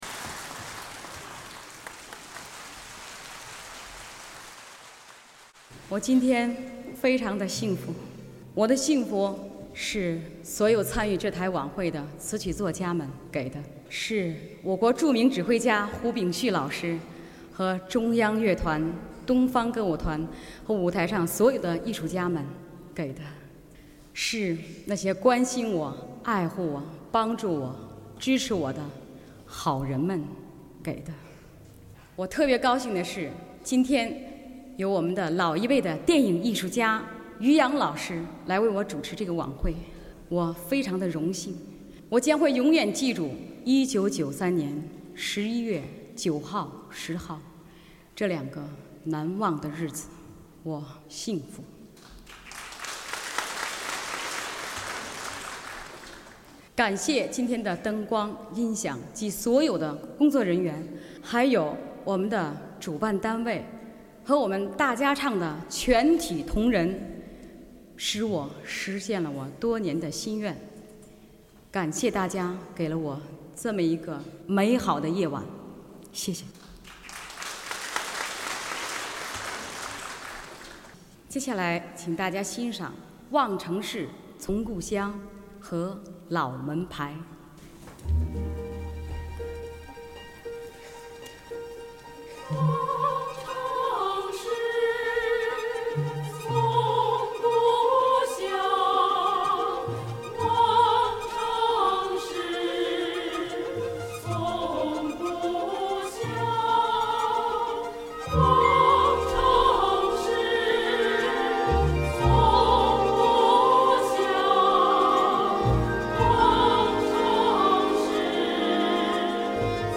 试听曲目：演唱会CD1片段：